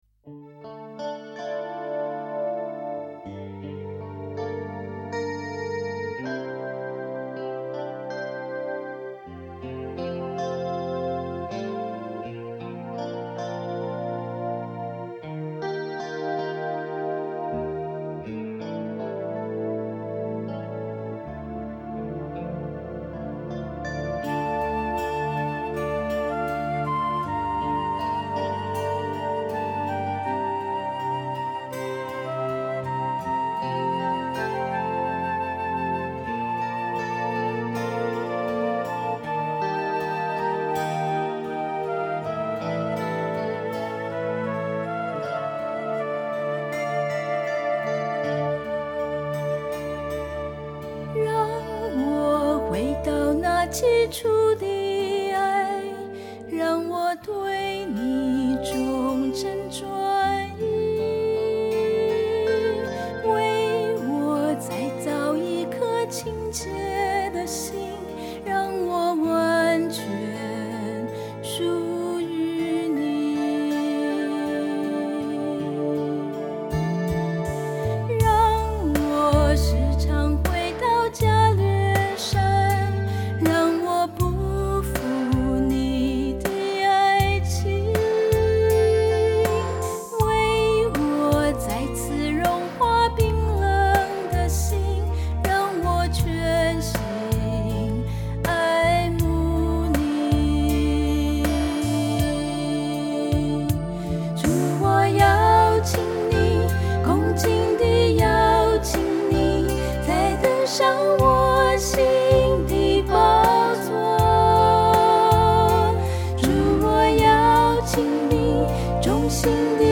原唱音樂